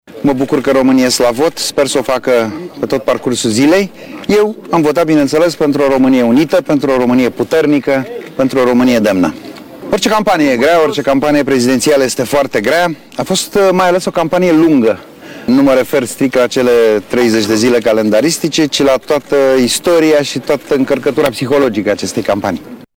El a venit la urne alături de soţia şi fiica sa, la o secţie organizată la Şcoala gimnazială nr. 12 din Capitală.